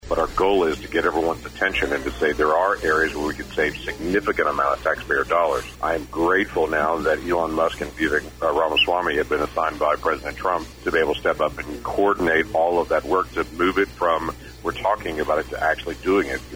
US Senator James Lankford (R-OK) called into Bartlesville Radio for his monthly podcast. Lankford talked about getting the Defense Bill finished and government waste.